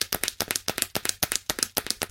Звук бегущего мультяшного червячка